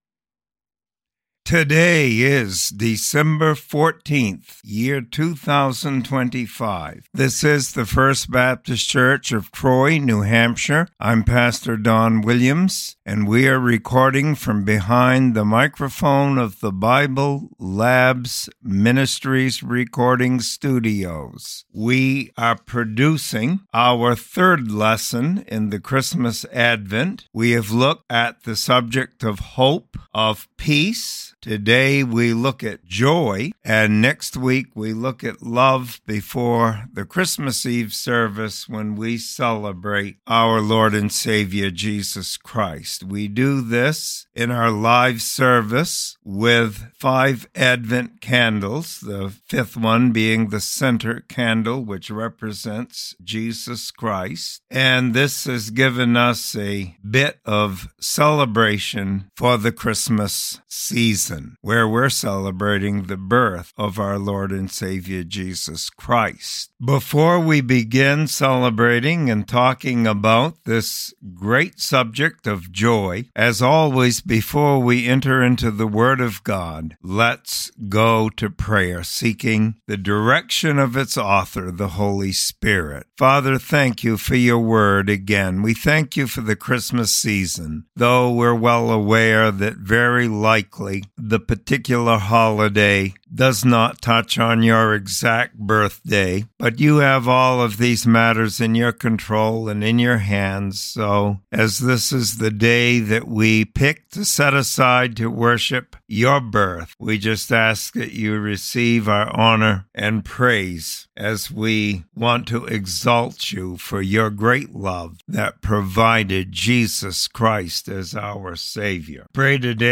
Sermon Library | First Baptist Church of Troy NH